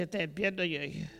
Localisation Soullans
Catégorie Locution